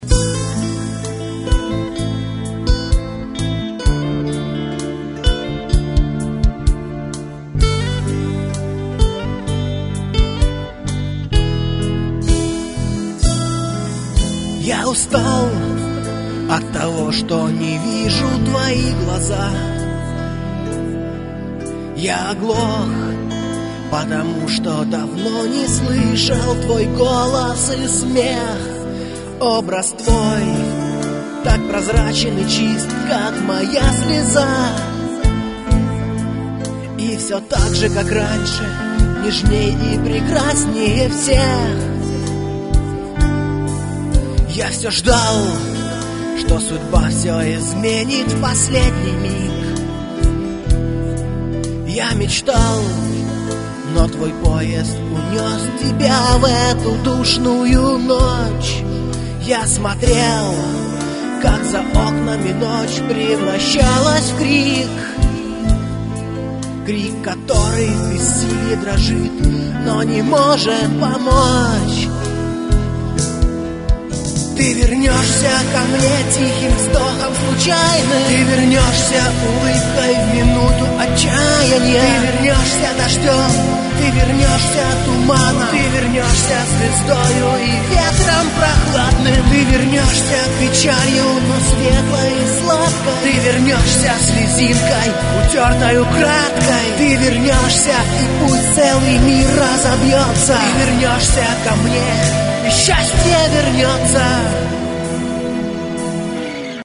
Гитары, клавиши, перкуссия, вокал
фрагмент (628 k) - mono, 48 kbps, 44 kHz